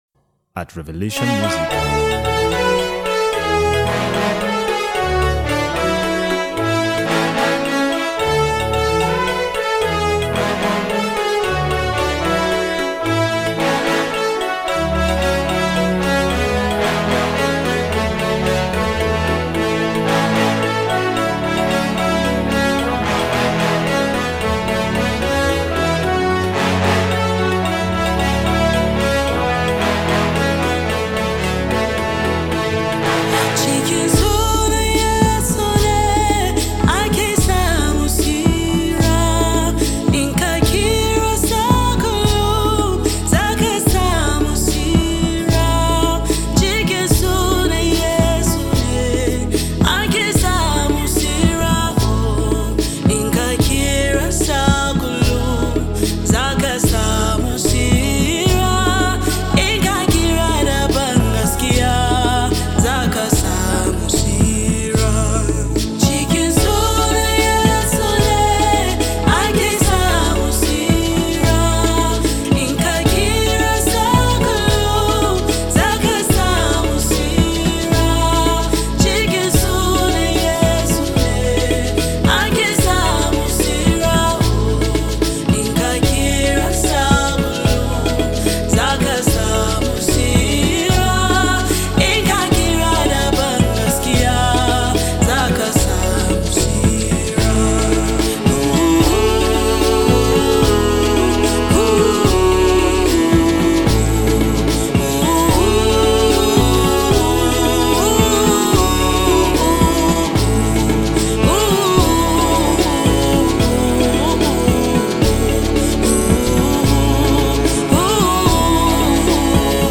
Latest Gospel Music 2025
With her anointed voice and spirit-filled lyrics